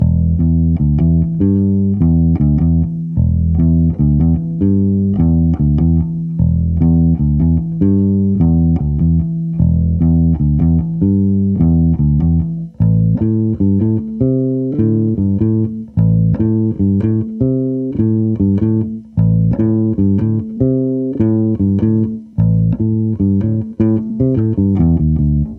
描述：贝斯吉他旋律
Tag: 75 bpm Rock Loops Bass Guitar Loops 2.15 MB wav Key : E